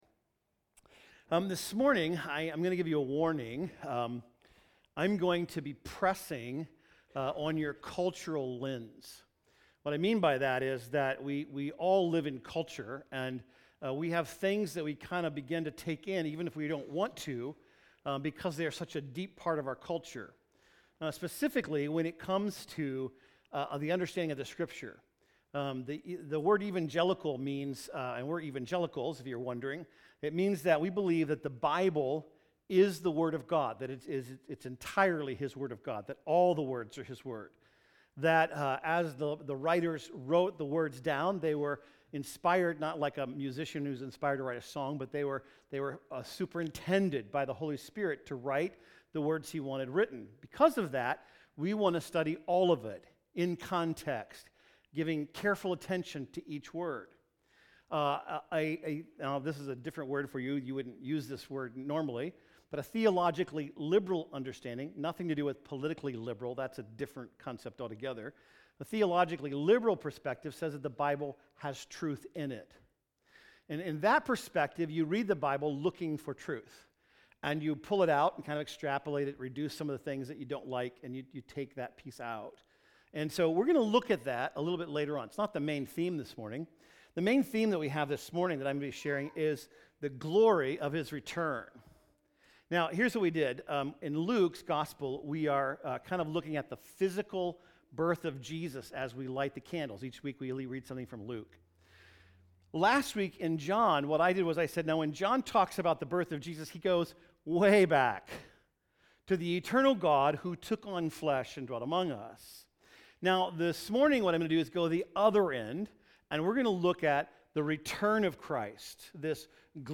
ABOUT STAFF WHAT WE BELIEVE NEWSLETTER MINISTRIES RVC Life Groups RVC Men RVC Women RVC Young Adults RVC Students RVC kids SIGN UP SERVE SERMON GIVE December 17, 2017 – We Will See…